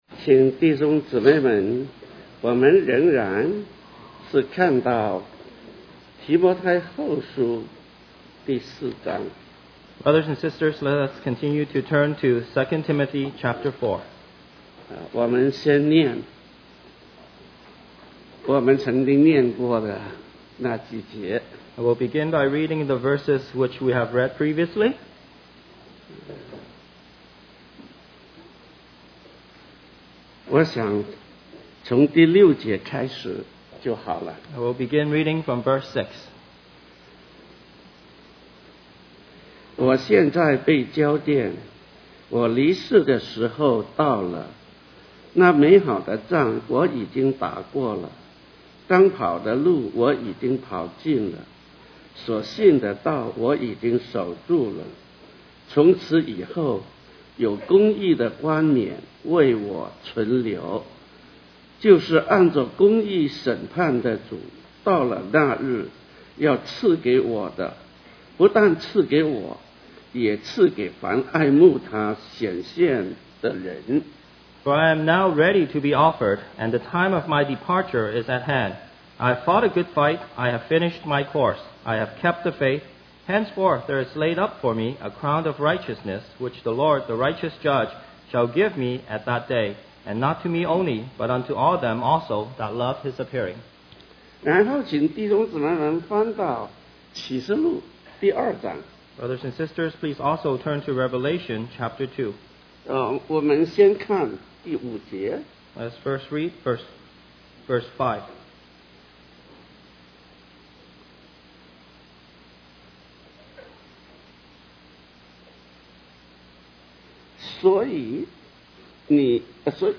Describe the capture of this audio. West Coast Christian Conference We apologize for the poor quality audio